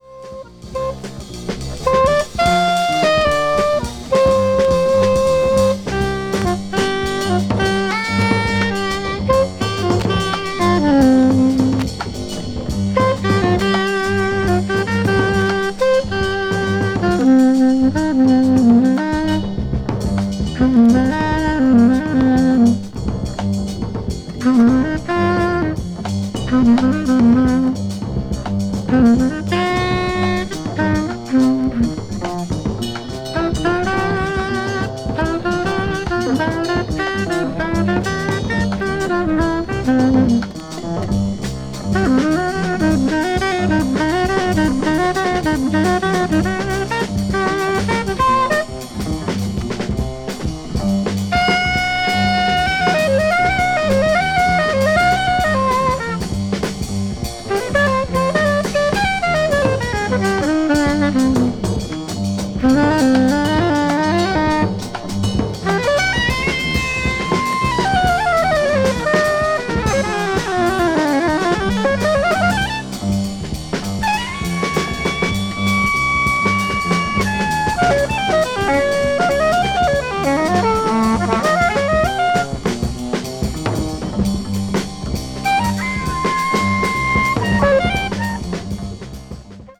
avant-jazz   free jazz   post bop   spiritual jazz